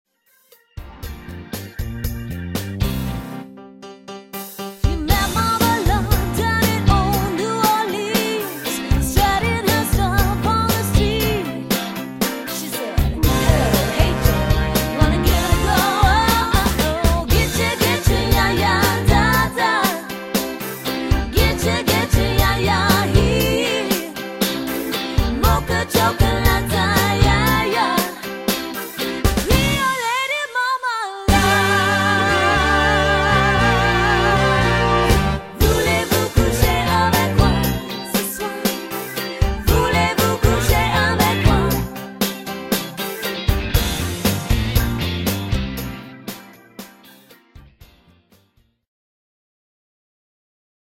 guitar
drums and keyboard.